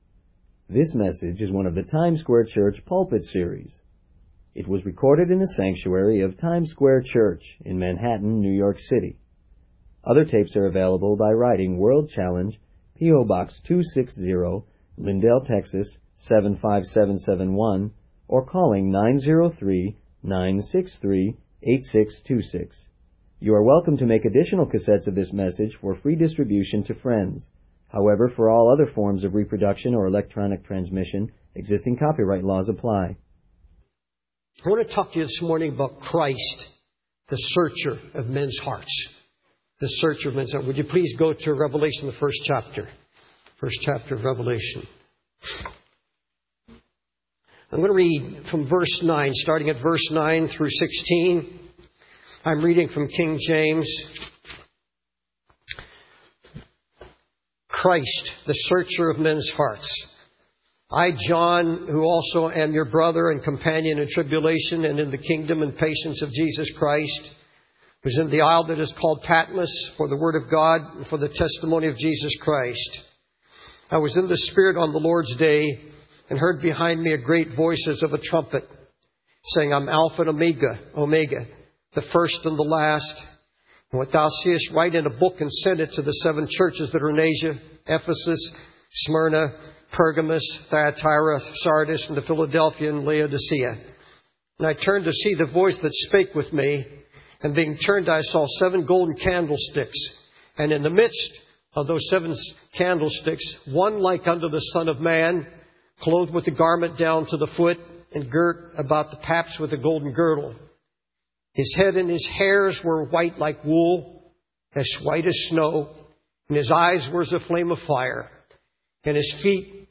In this sermon, the preacher emphasizes the importance of being willing to be searched by the Holy Ghost.